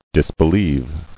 [dis·be·lieve]